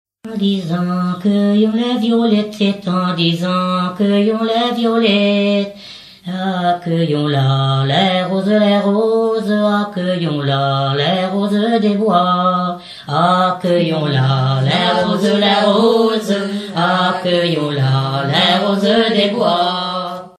gestuel : à marcher
Genre énumérative
Chanteuse du pays de Redon